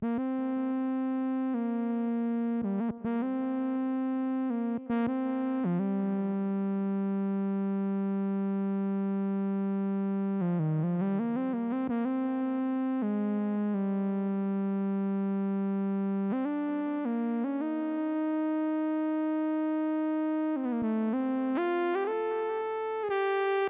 11 lead B.wav